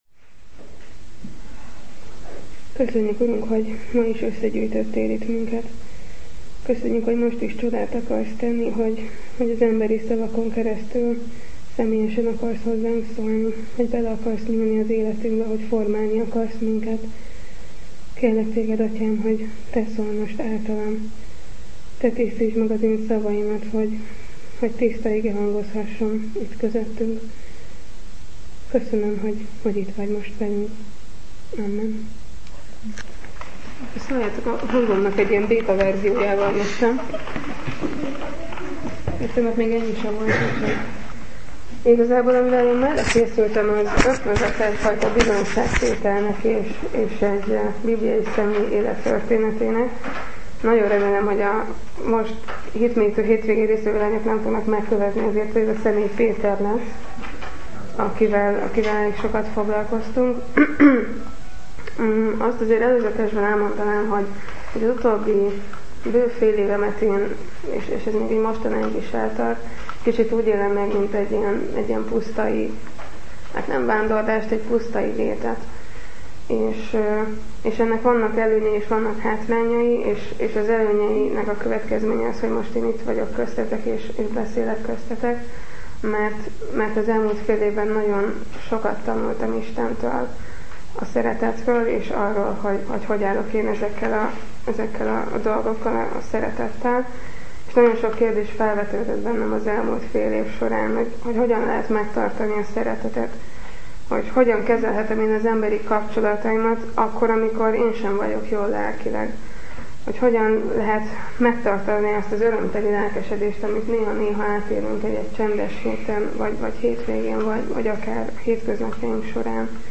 Bizonyságtétel